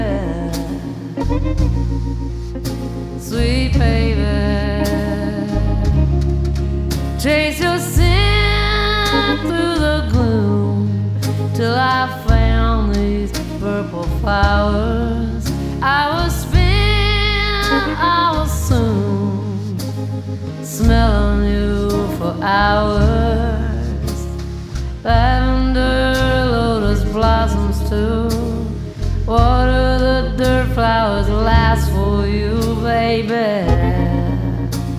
Жанр: Поп музыка / Рок / Альтернатива / Фолк / Кантри